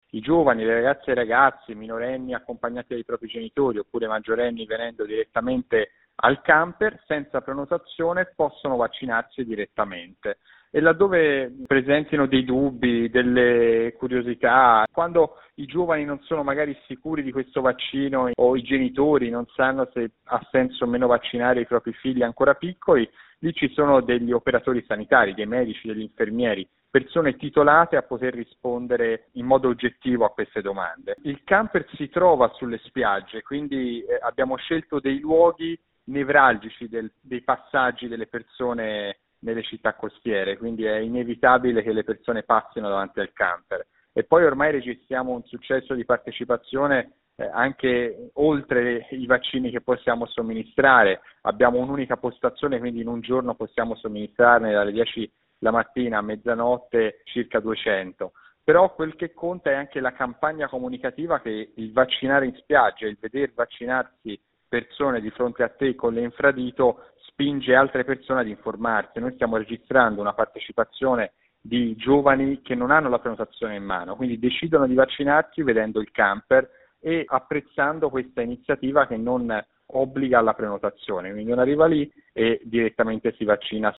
Ce ne parla il consigliere regionale alle Politiche giovanili, Bernard Dika: